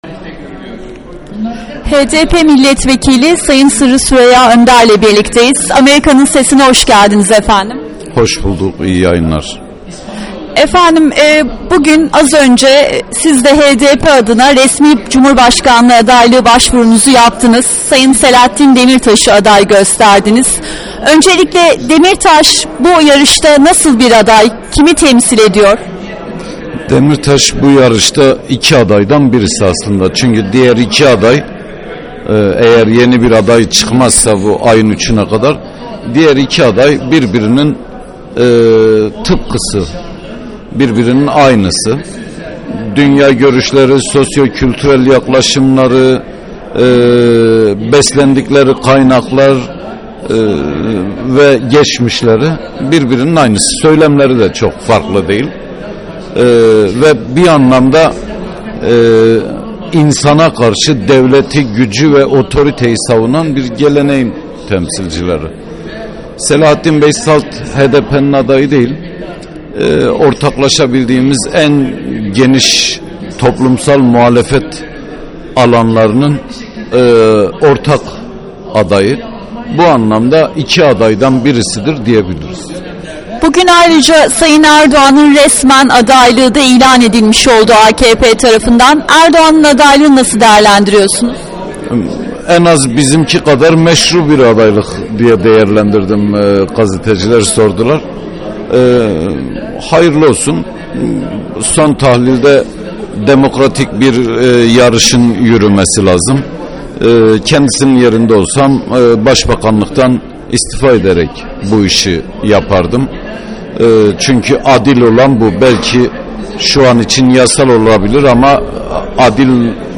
Sırrı Süreyya Önder ile Söyleşi